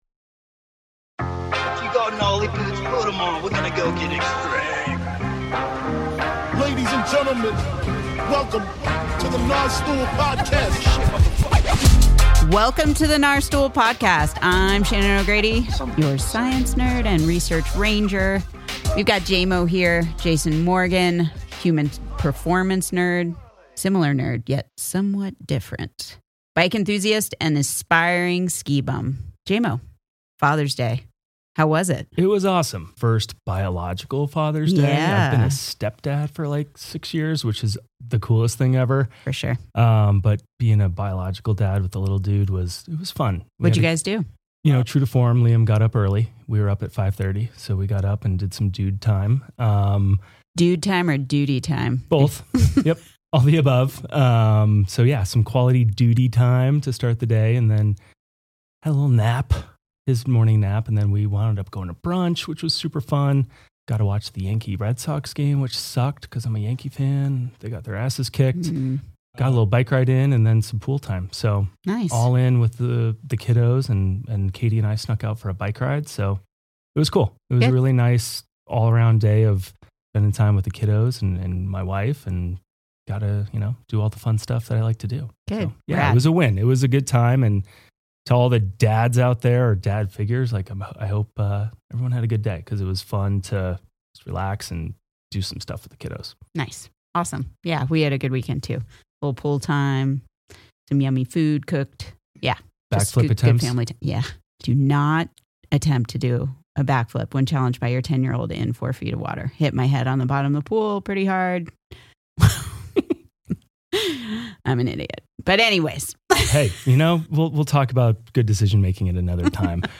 This is a raw and powerful conversation about what it means to show up for yourself, your people, and your community.